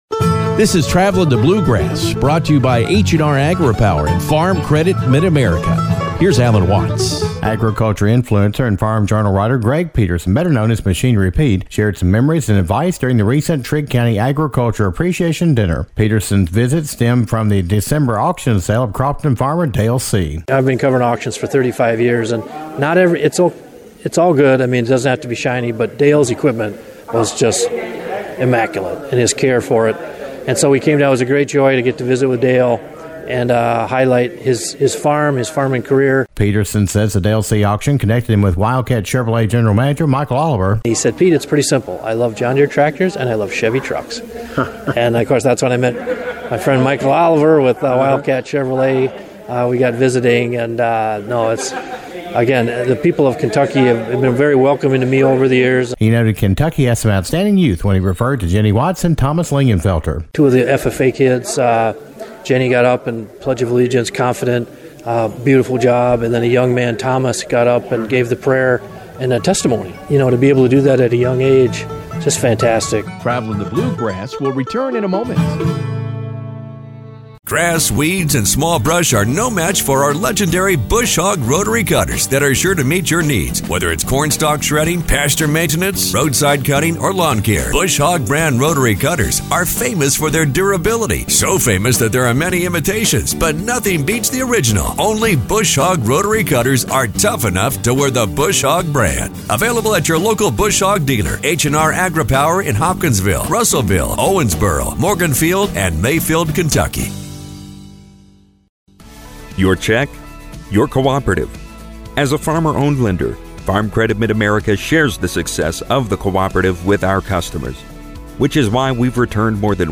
brought an encouraging message during the Trigg County Agriculture Appreciation Dinner March 13th.